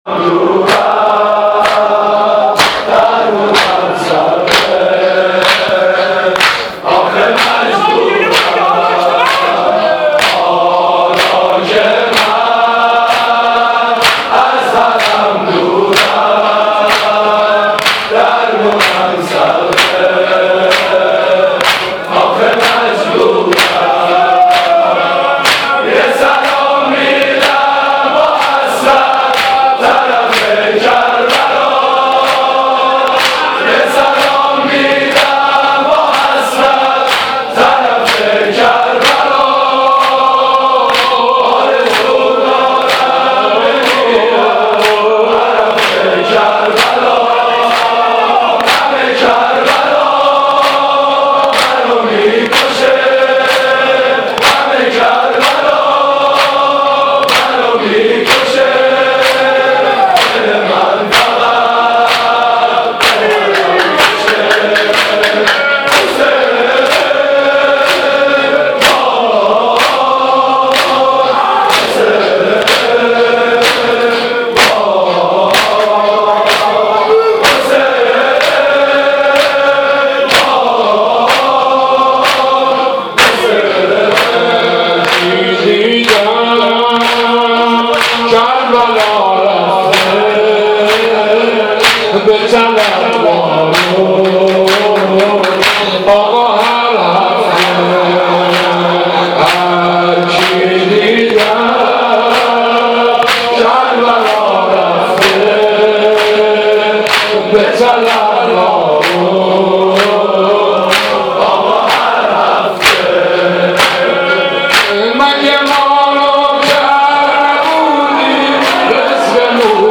عزاداری